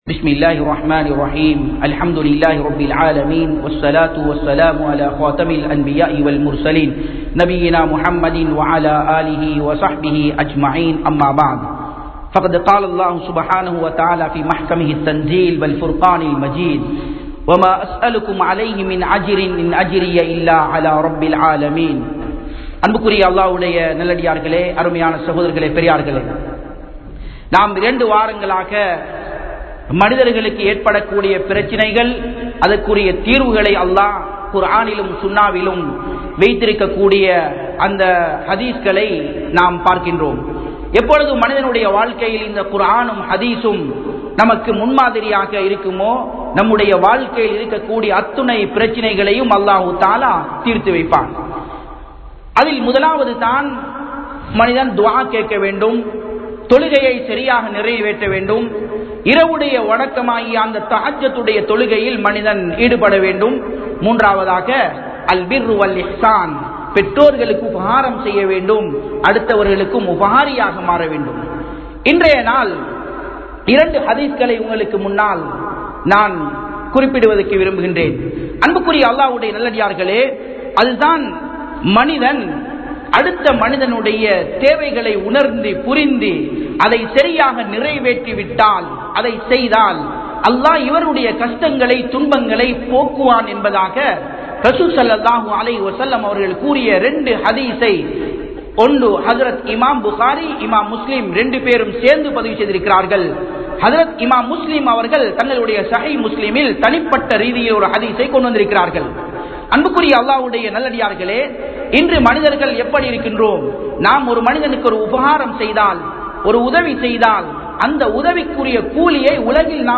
Mattravarhalin Kuraihalai Maraiungal (மற்றவர்களின் குறைகளை மறையுங்கள்) | Audio Bayans | All Ceylon Muslim Youth Community | Addalaichenai
Japan, Nagoya Port Jumua Masjidh